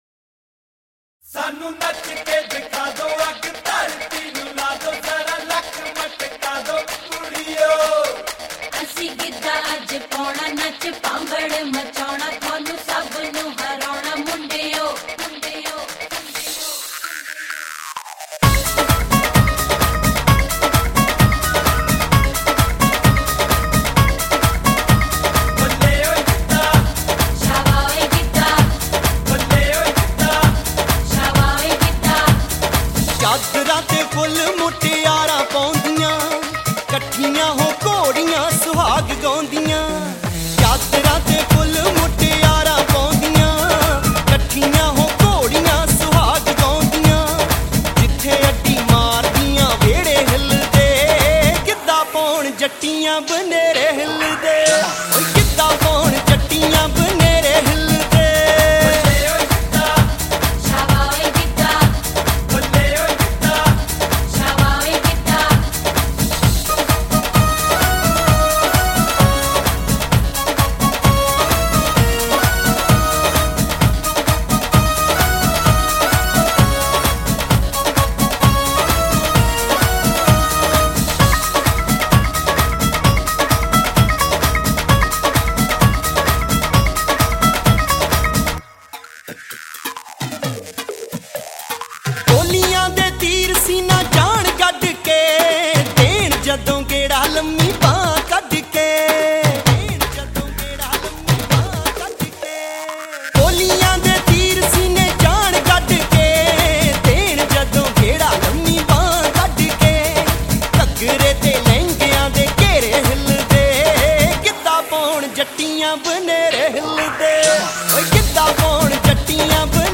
Punjabi Bhangra